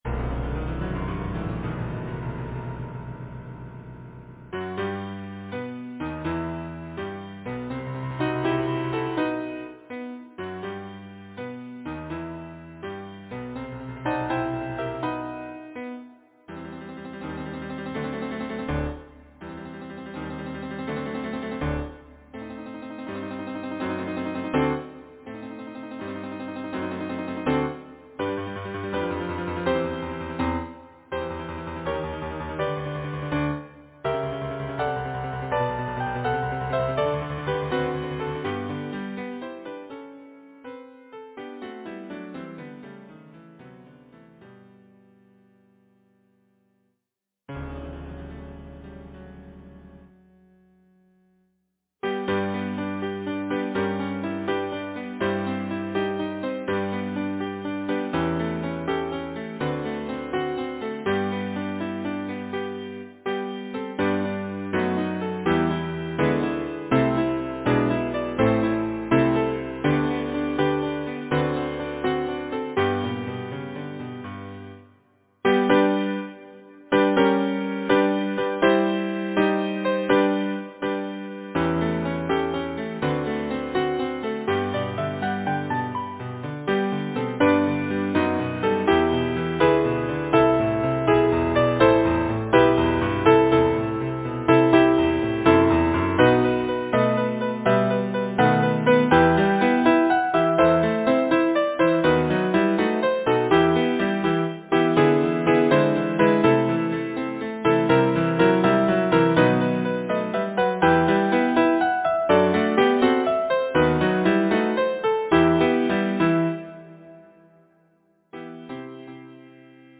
Title: The Lifeboat Composer: William Webster Pearson Lyricist: A. Earnshawcreate page Number of voices: 4vv Voicing: SATB Genre: Secular, Partsong
Language: English Instruments: Piano